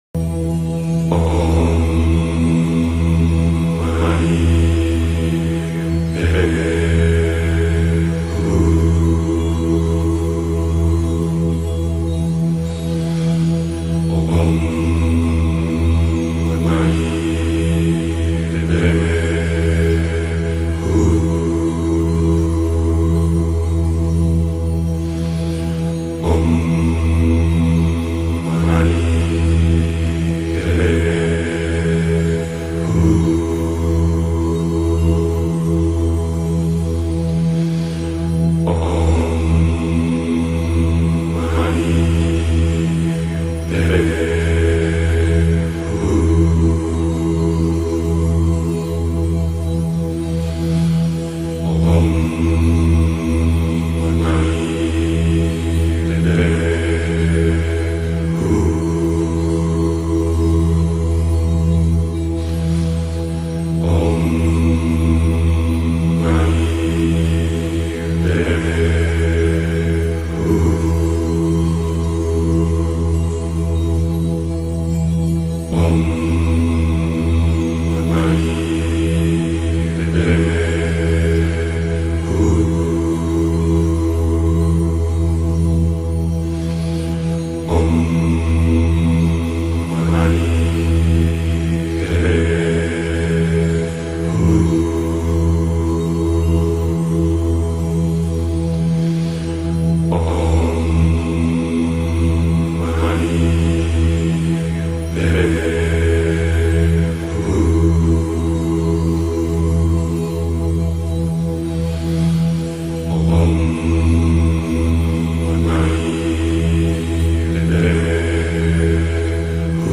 Om Mani Padme Hum – Monjes tibetanos